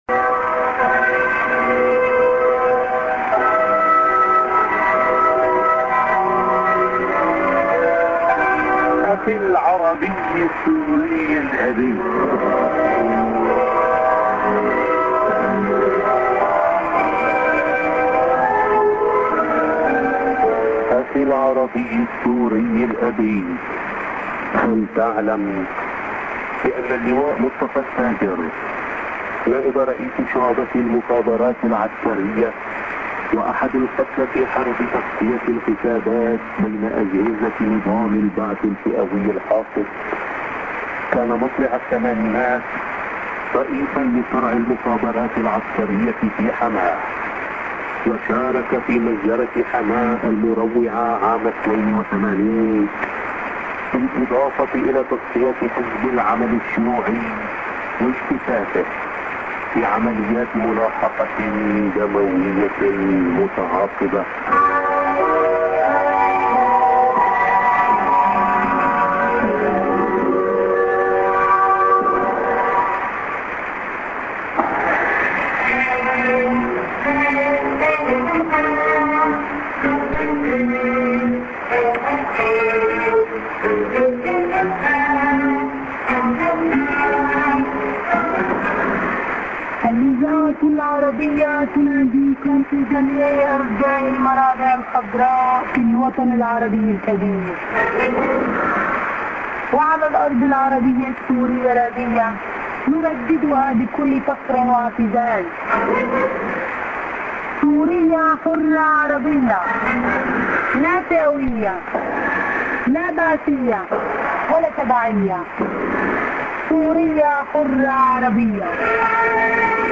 music->20":ANN(women:ID)->01'05":NA-> 00:30 s/off